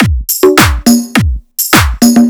104 BPM Beat Loops Download